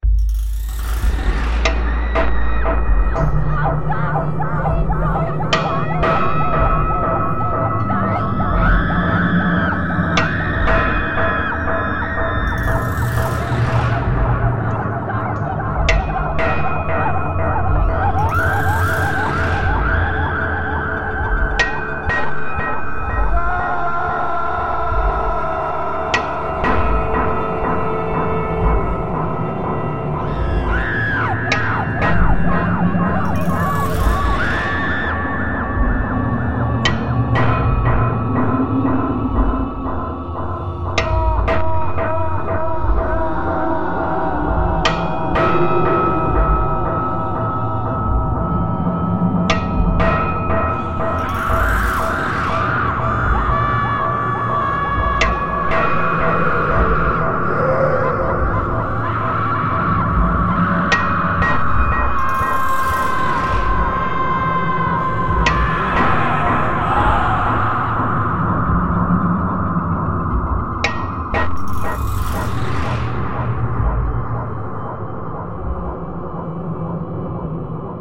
Listen between the monologues and you'll hear the truth.